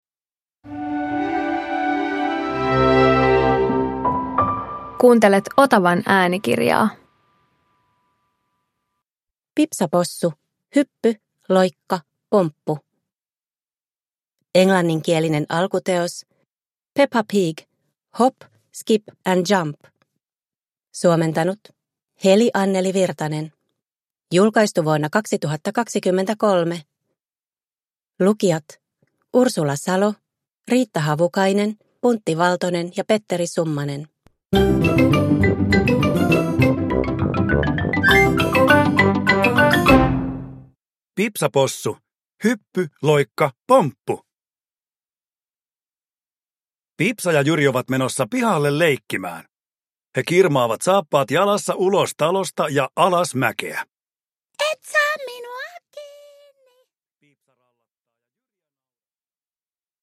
Pipsa Possu - Hyppy, loikka, pomppu – Ljudbok